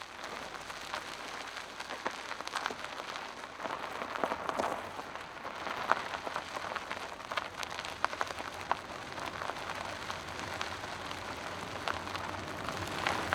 Vehicles, Tire, Car, Wheel On Gravel, Tire Grit SND0054.wav